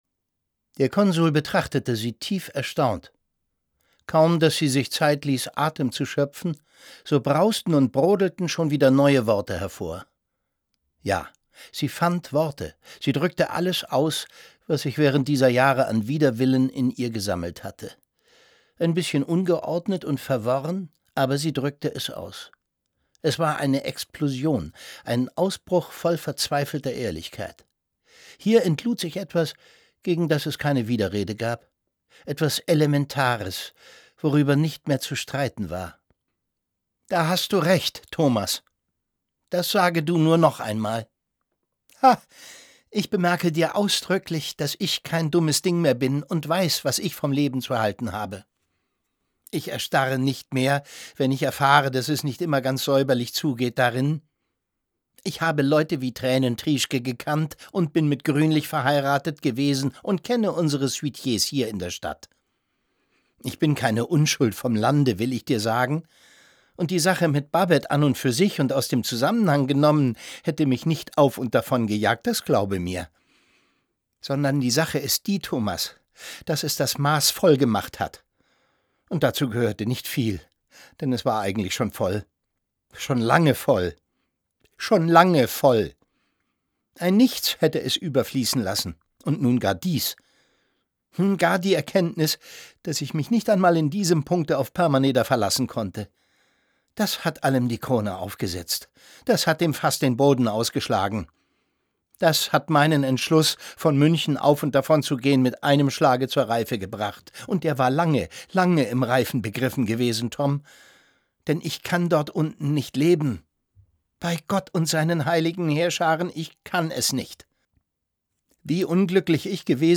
Es liest Thomas Sarbacher.